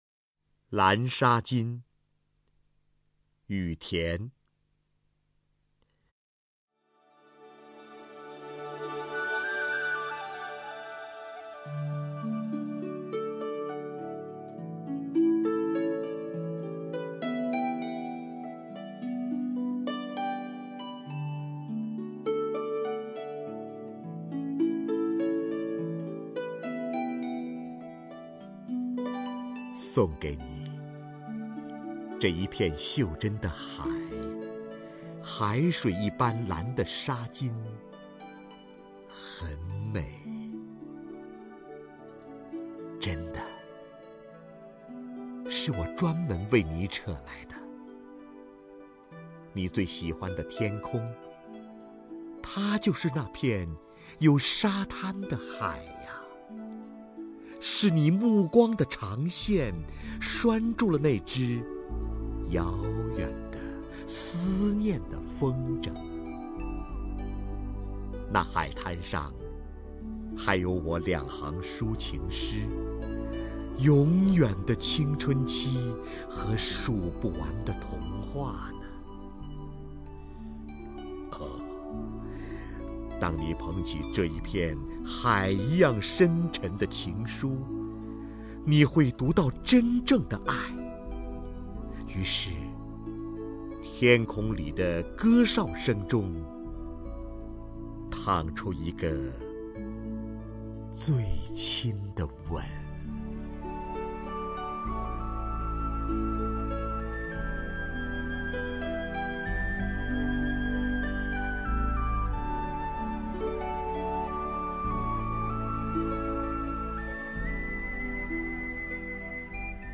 首页 视听 名家朗诵欣赏 瞿弦和
瞿弦和朗诵：《蓝纱巾》(雨田)